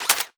Plasma Rifle
Added more sound effects.
GUNMech_Reload_02_SFRMS_SCIWPNS.wav